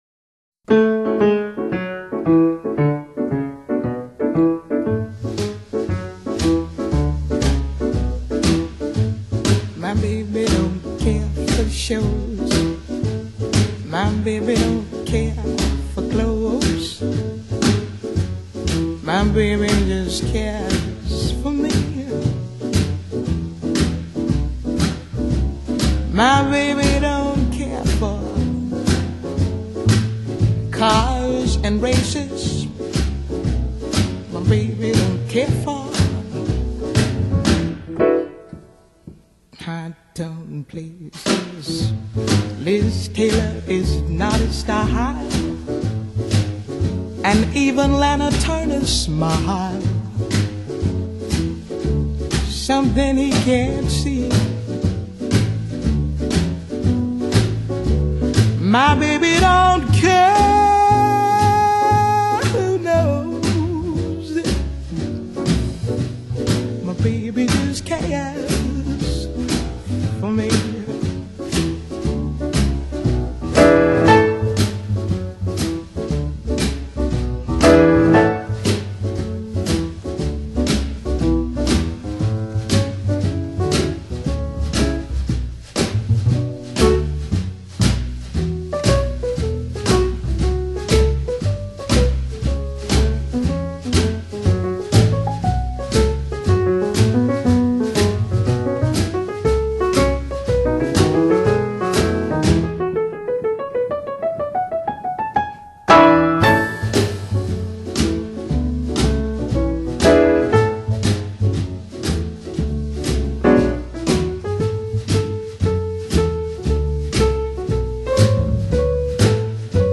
【醉人爵士】